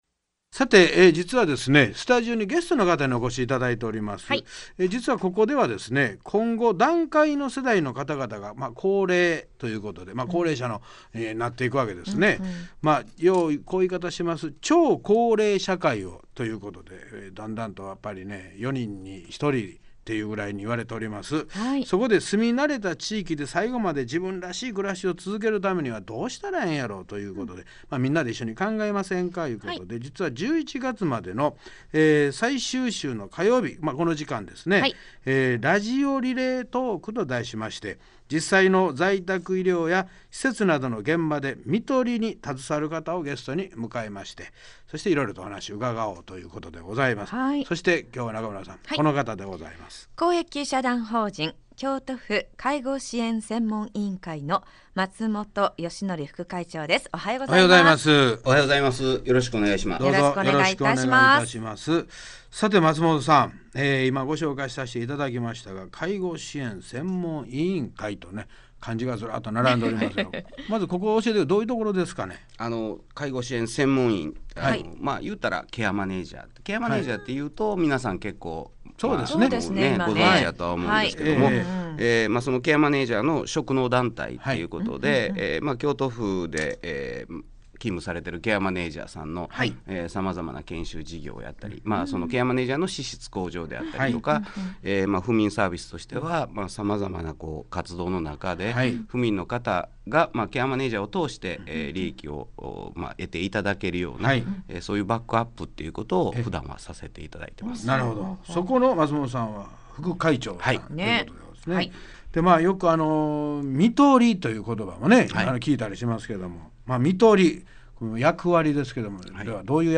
在宅や施設で看取りに取り組む医師、訪看、施設職員、介護支援専門員等をゲストに迎え、心に残る看取りの実践事例などをお話いただきました。
(KBS京都「笑福亭晃瓶のほっかほかラジオ」内で実施)